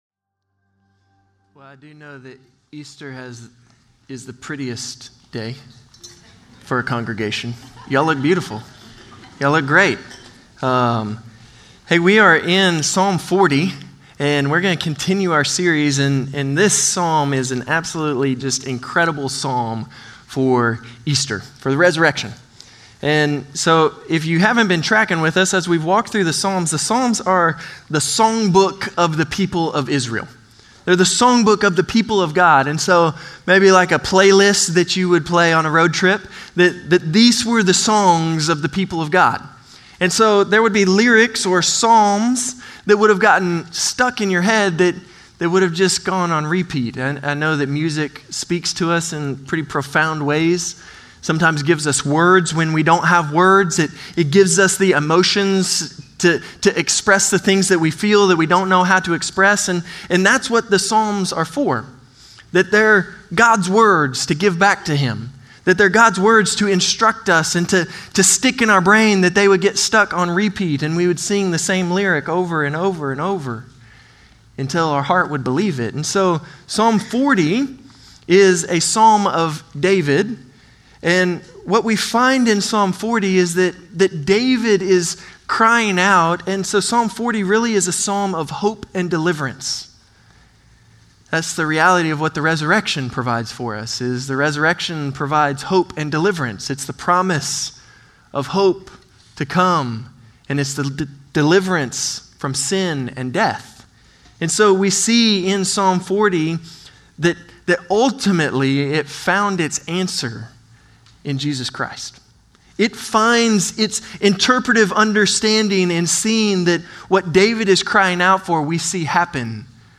Norris Ferry Sermons Apr. 20, 2025 -- The Book of Psalms -- Psalm 40 (Easter) Apr 20 2025 | 00:37:06 1x 00:00 / 00:37:06 Subscribe Share Spotify RSS Feed Share Link Embed